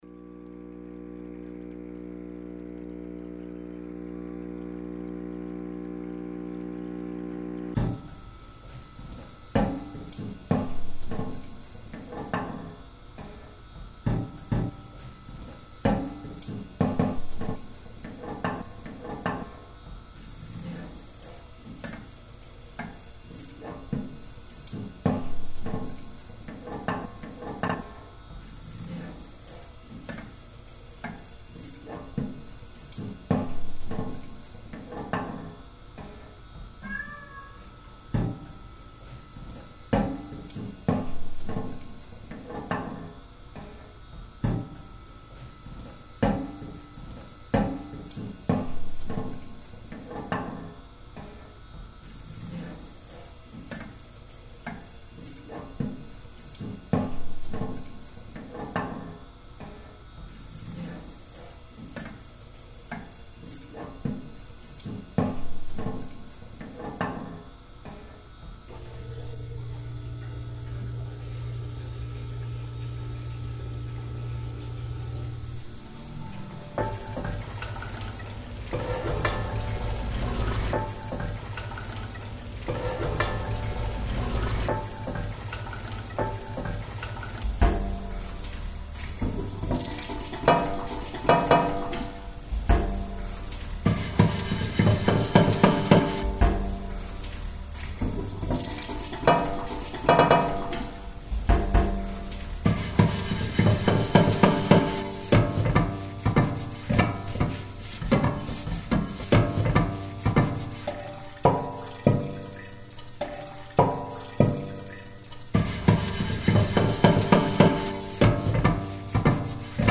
Processed Field Recordings
Refined collage-techniques